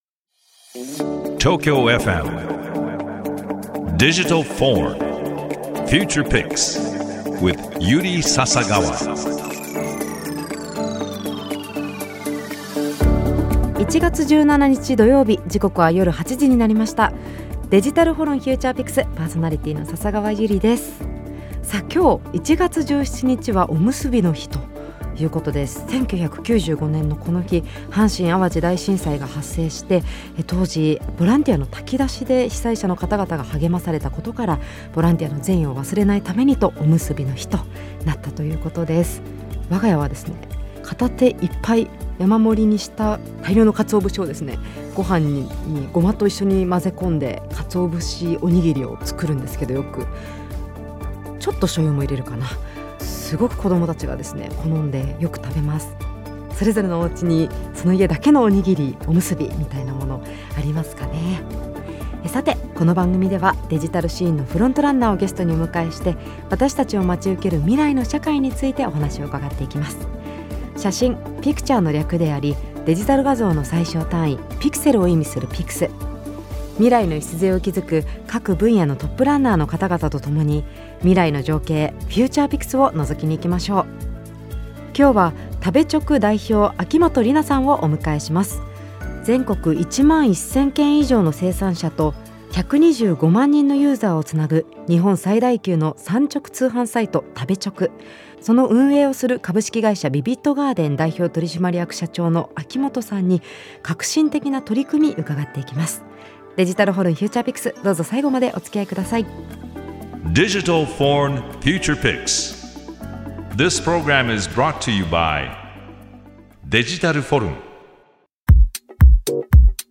デジタルシーンのフロントランナーをゲストにお迎えして、 私達を待ち受ける未来の社会についてお話を伺っていくDIGITAL VORN Future Pix。